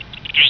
bird_9.wav